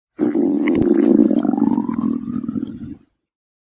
Download Free Stomach Sound Effects
Stomach